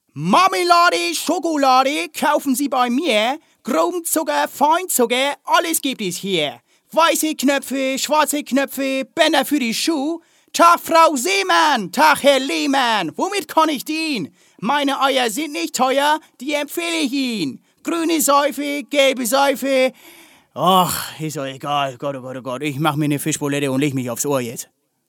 Sprecher, Synchronsprecher, Schauspieler